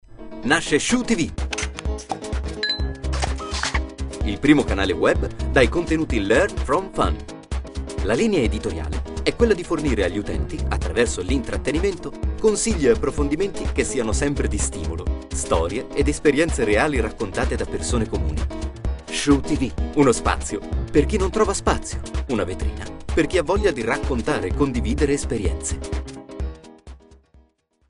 stimmalter:
Sprechprobe: Sonstiges (Muttersprache):